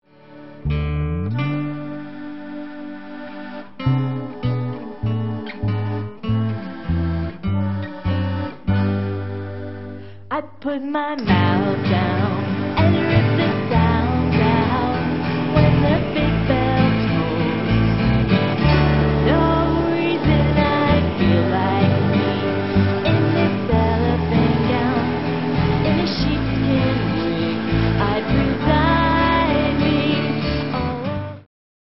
guitar, violin, toy xylophone
clarinet, accordion, mandolin, guitar, tin whistle